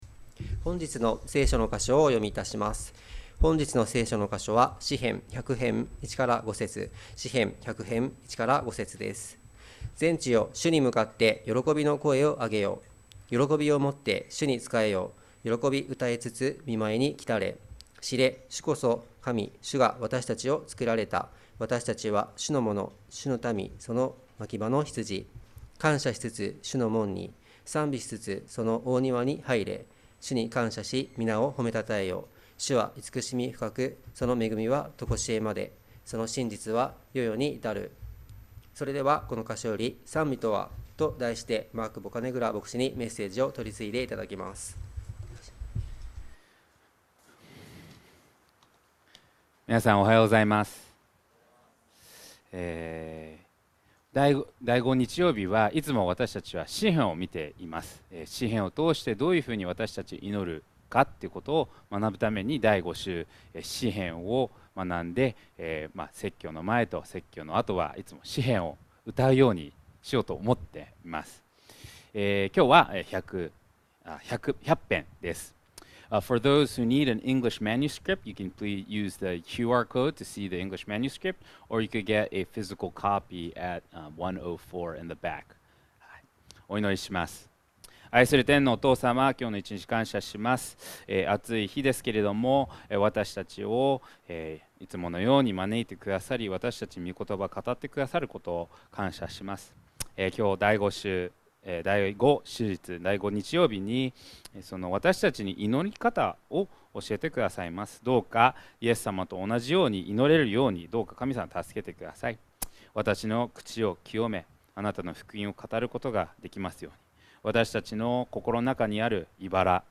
2025年6月29日礼拝 説教 「賛美とは？！」 – 海浜幕張めぐみ教会 – Kaihin Makuhari Grace Church